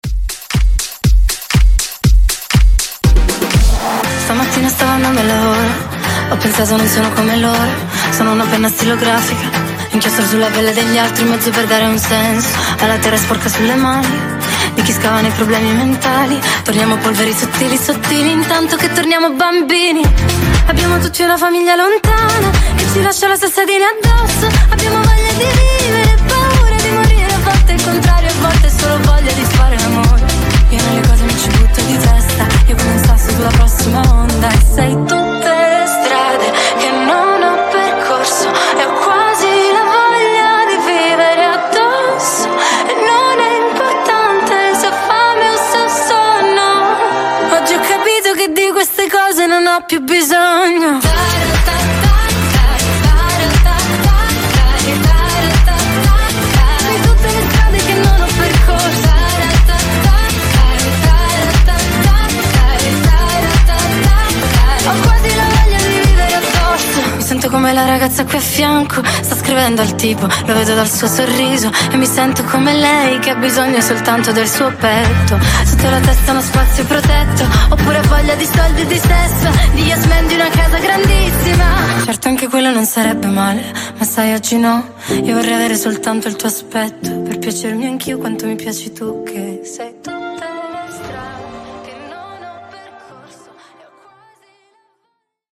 Genres: DANCE , RE-DRUM , TOP40 Version: Clean BPM: 120 Time